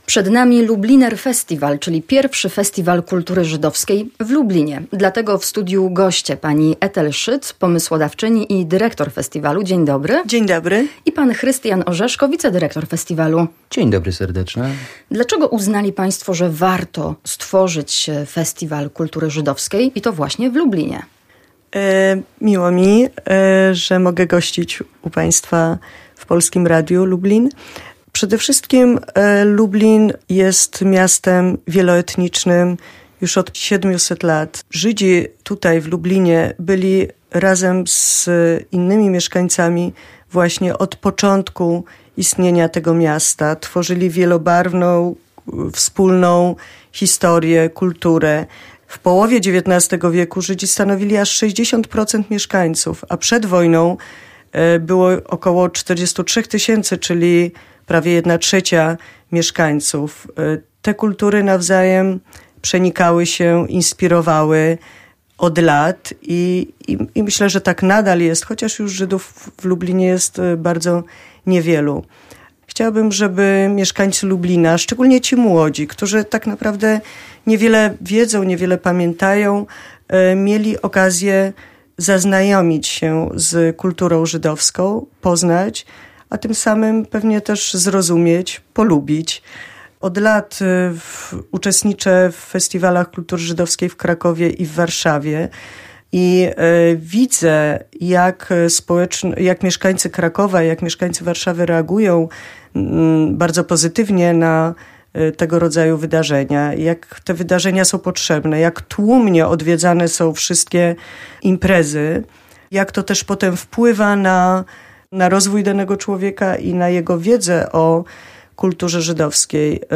O wydarzeniu w studiu Drugiej Połowy Dnia mówili organizatorzy